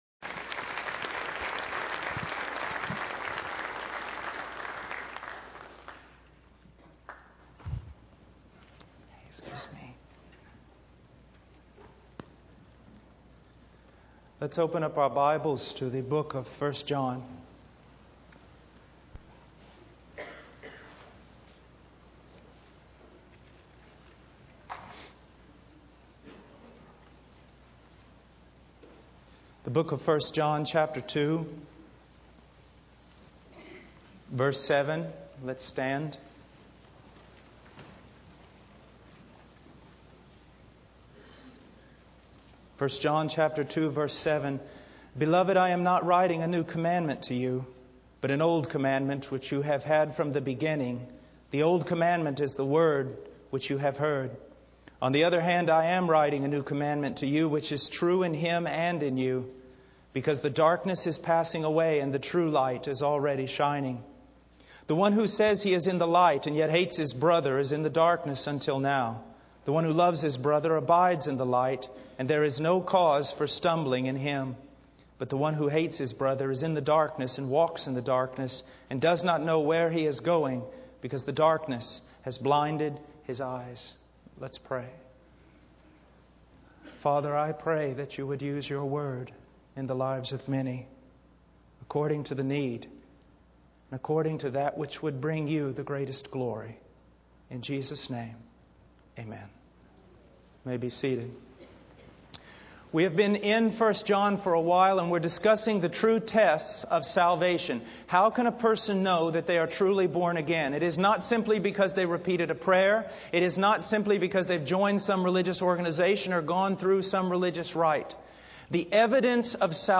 In this sermon, the preacher emphasizes the importance of love and its connection to God.